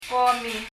よく聴いてみると、k なのか g なのか、微妙な音に聞こえますが・・・